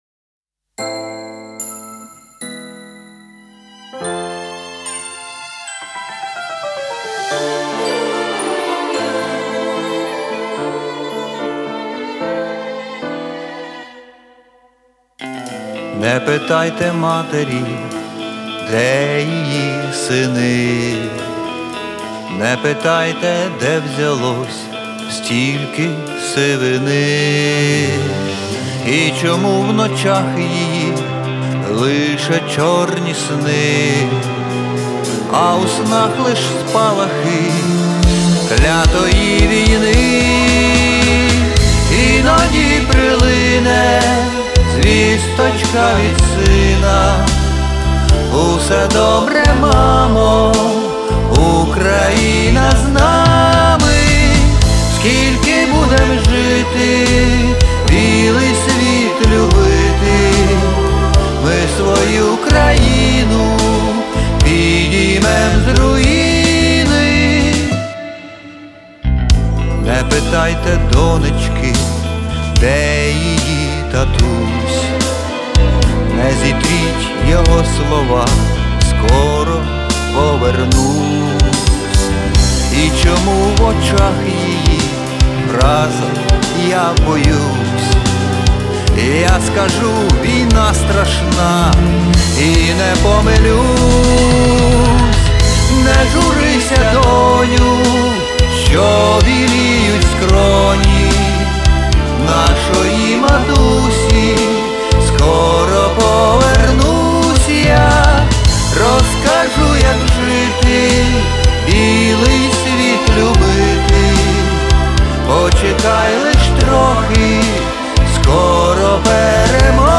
Стиль: Балада